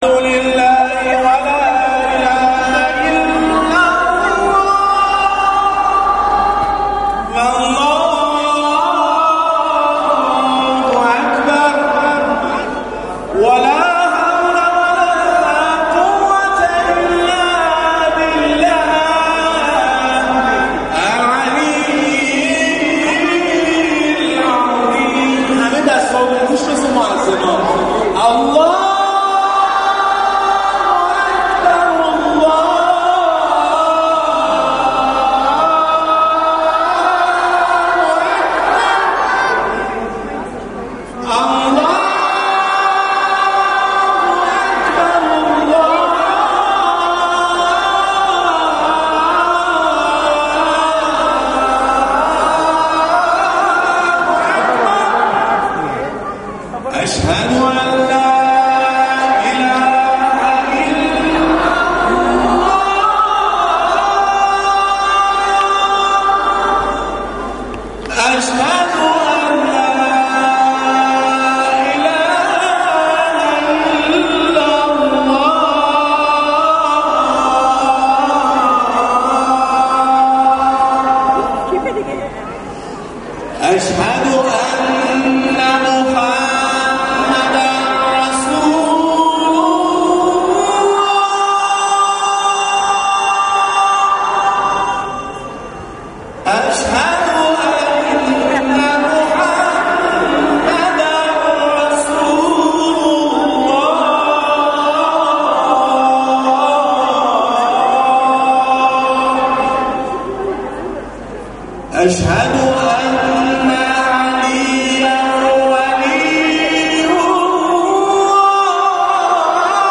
همچنین چند مؤذن نوجوان به گفتن اذان پرداختند و در نهایت از برگزیدگان بخش‌های مختلف تقدیر به عمل آمد.
اذان دسته جمعی همه مؤذنان کودک و نوجوان حاضر در جلسه